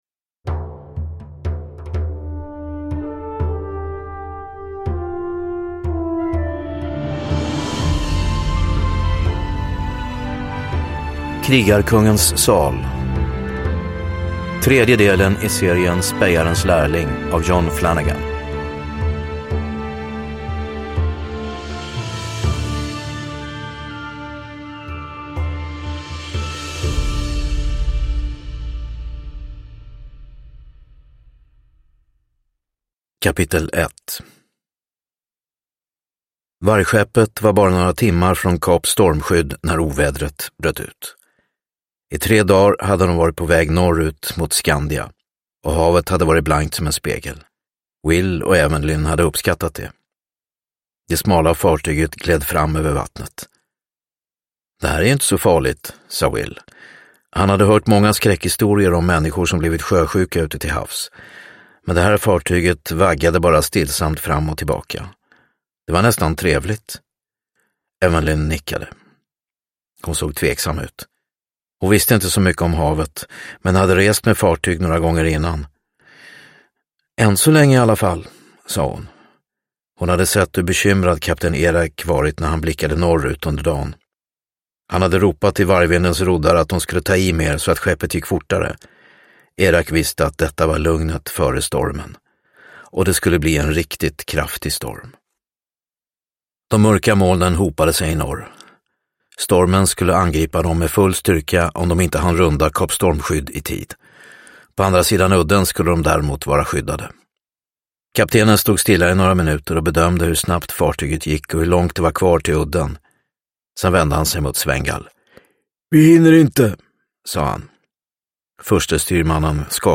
Krigarkungens sal – Ljudbok – Laddas ner